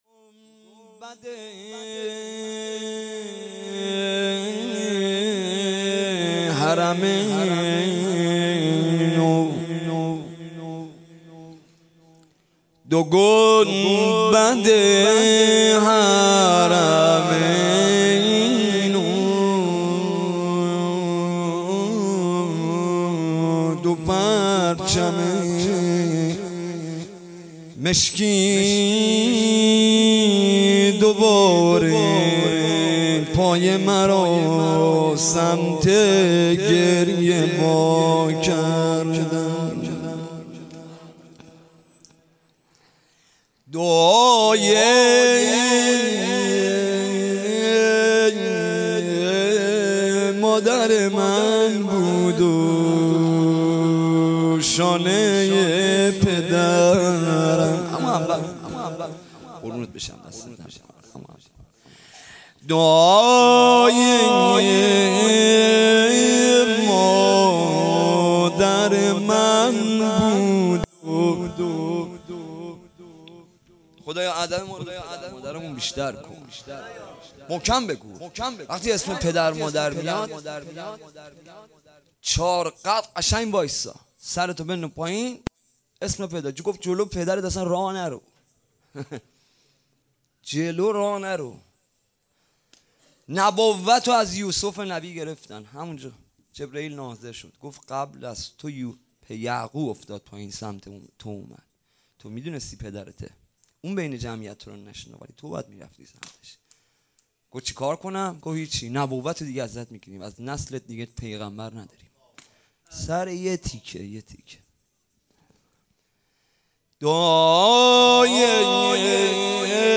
شب دوم محرم 95
روضه
شور